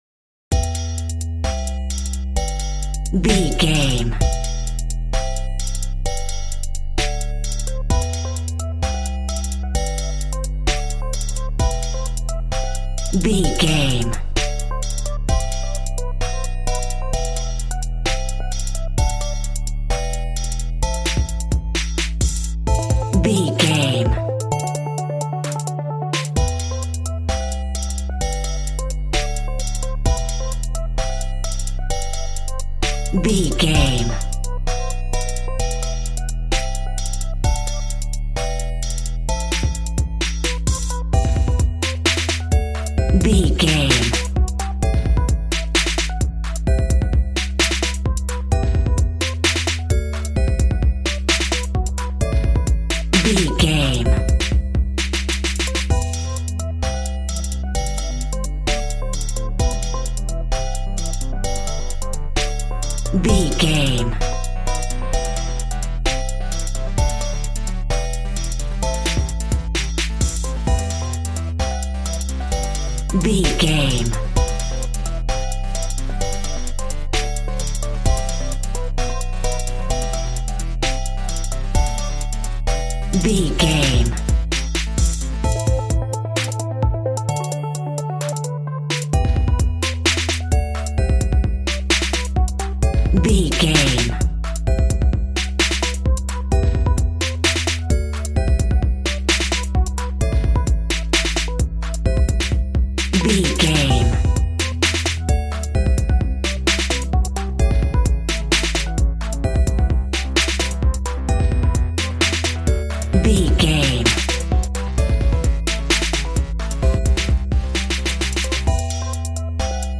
Aeolian/Minor
calm
smooth
synthesiser
piano